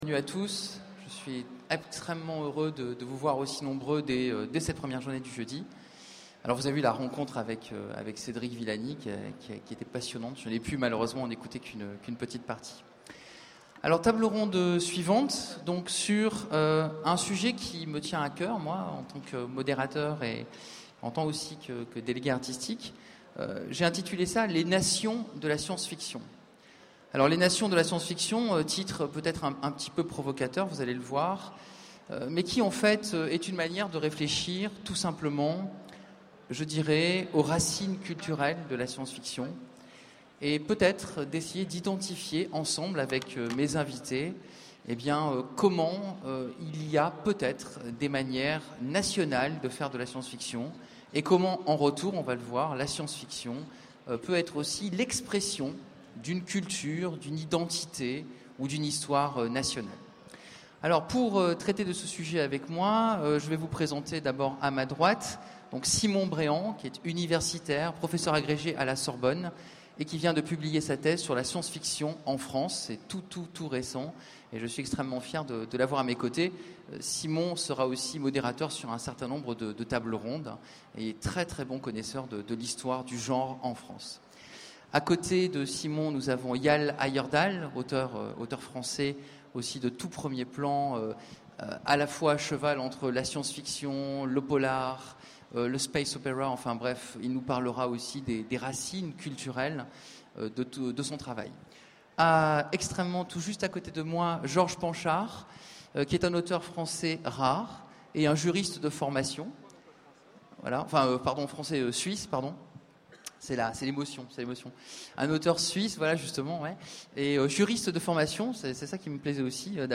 Utopiales 12 : Conférence Les nations de la sience-fiction